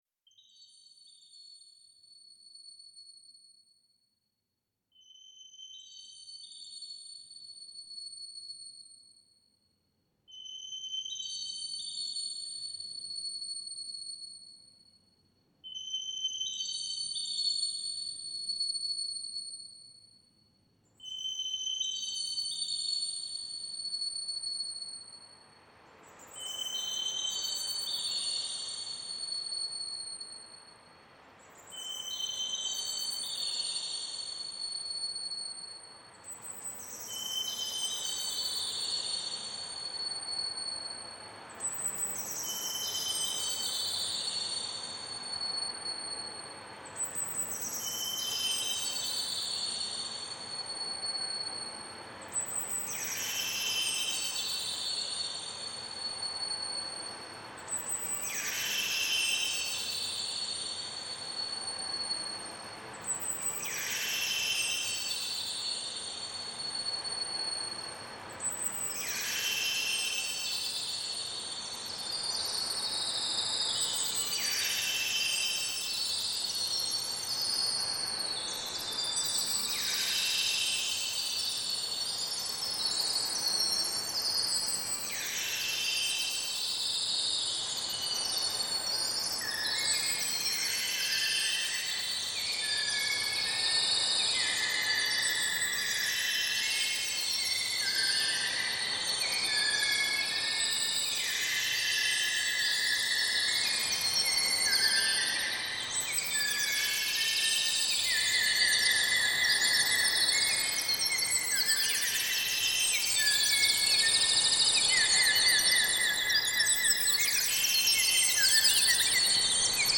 BIRDS.mp3